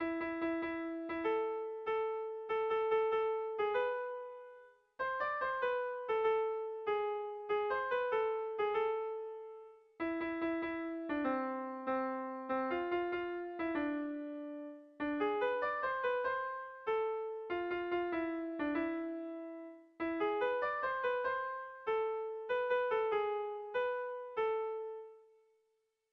Bertso melodies - View details   To know more about this section
Gipuzkoa < Basque Country
Zortziko txikia (hg) / Lau puntuko txikia (ip)